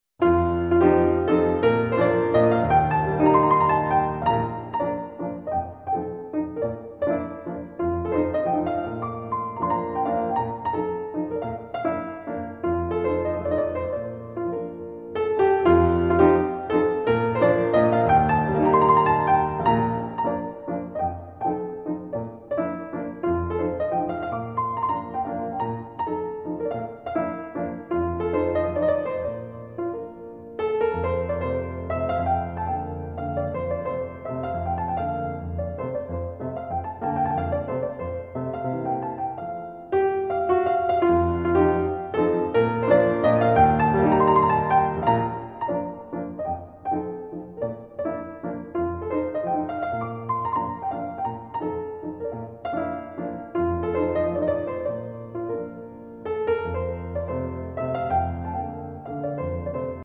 Fryderyk Chopin - Mazurkas Op 7 - 1 in B flat minor